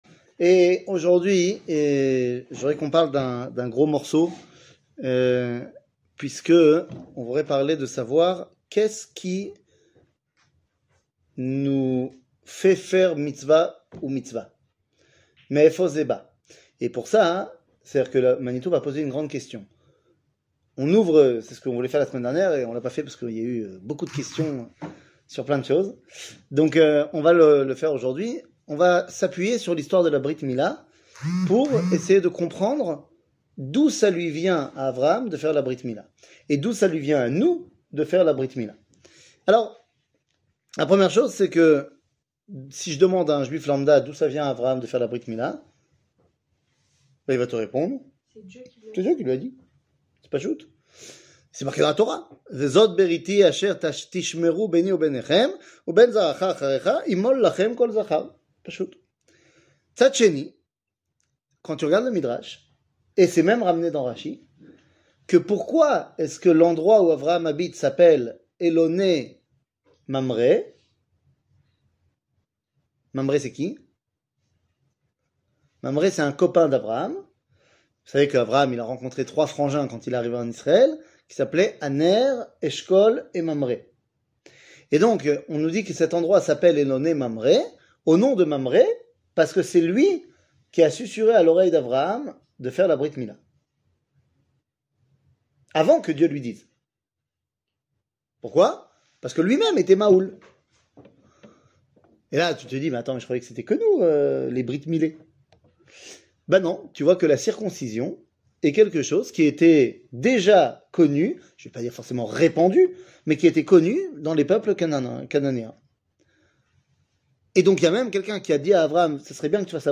La Torah de Manitou, 08, la revelation 00:44:39 La Torah de Manitou, 08, la revelation שיעור מ 08 ינואר 2023 44MIN הורדה בקובץ אודיו MP3 (40.87 Mo) הורדה בקובץ וידאו MP4 (81.66 Mo) TAGS : שיעורים קצרים